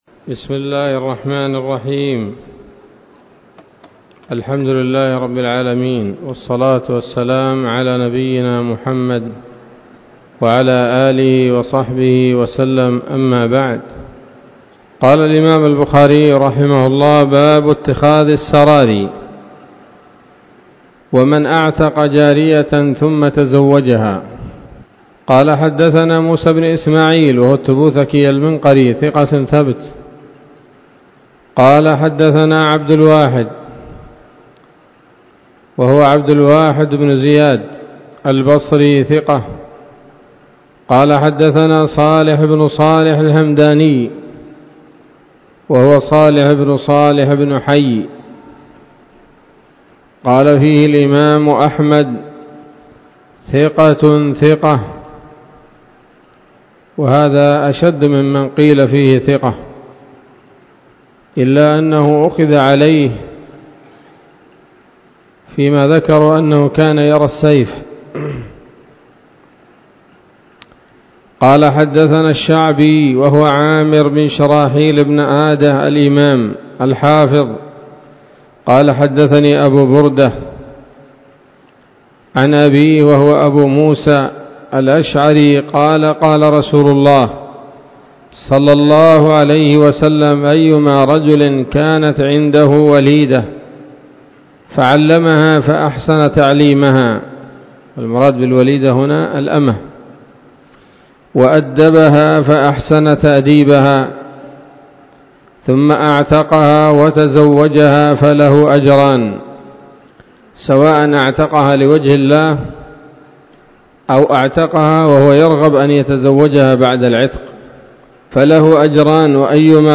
الدرس الثاني عشر من كتاب النكاح من صحيح الإمام البخاري